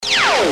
mininglaser.ogg